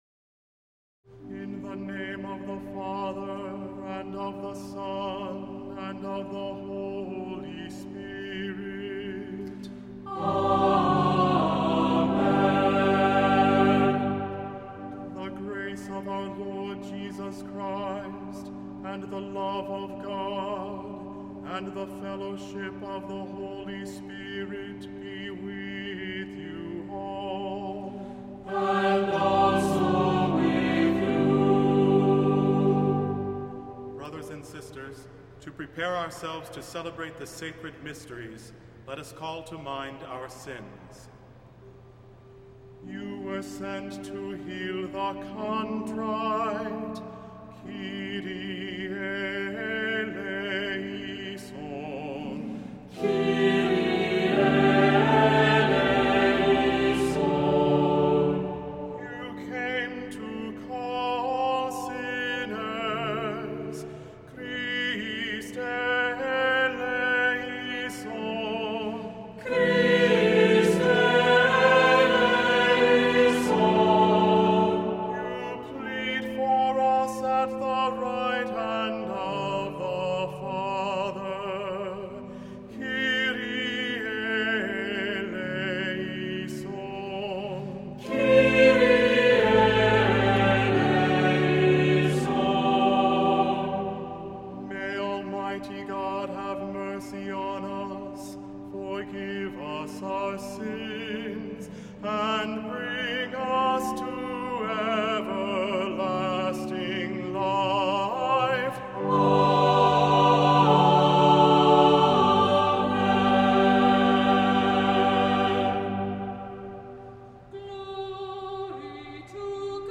Voicing: Unison; SATB; Priest; Assembly